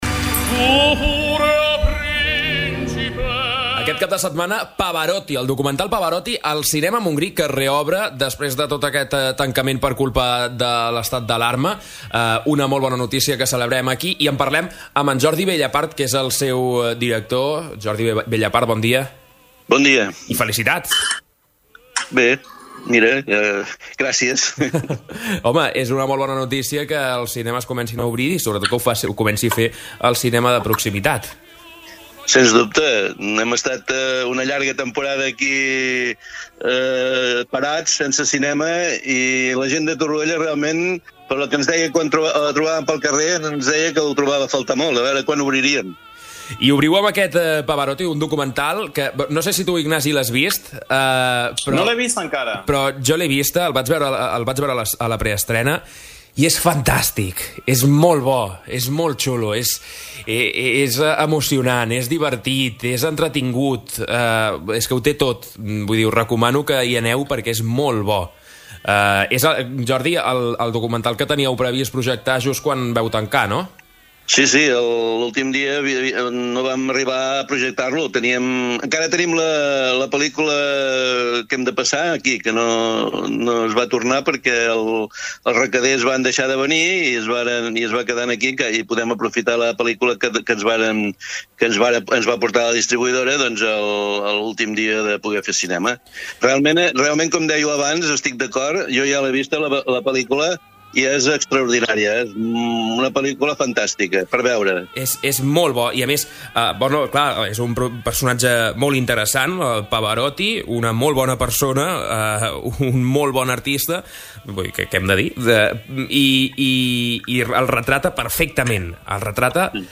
Entrevistes SupermatíTorroella de Montgrí - l'Estartit